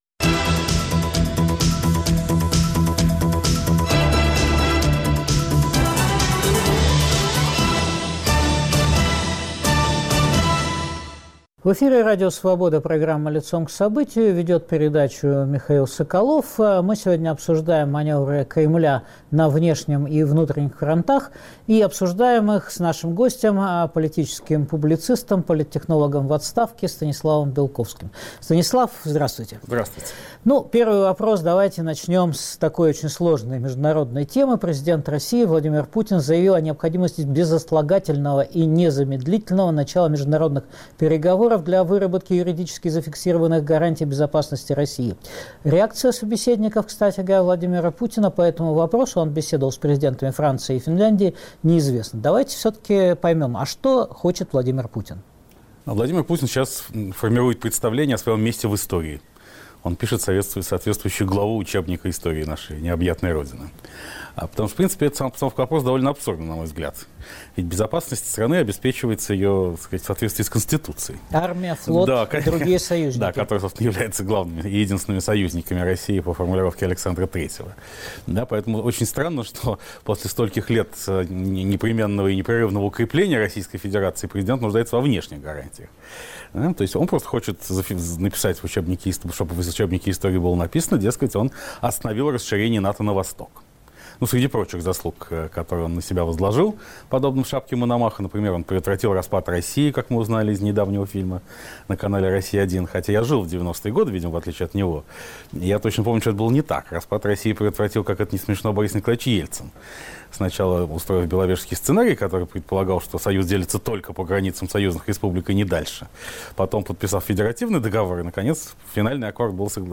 Маневры хозяев Кремля на внешнем и внутренних фронтах обсуждаем с политологом Станиславом Белковским.